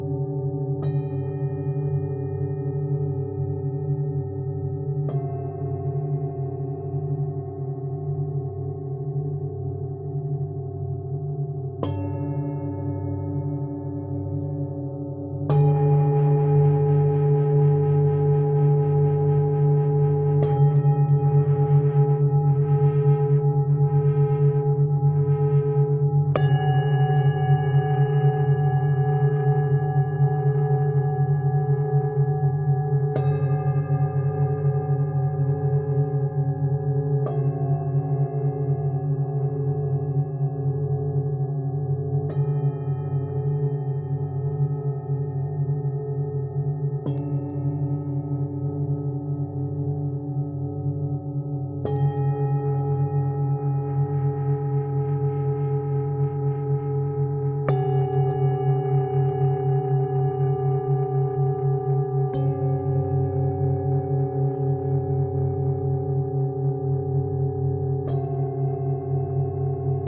Sound Bath Recording
Get the jolt of personal power and self-confidence you’re looking for in this Online Sound Bath & Meditation with targeted frequencies.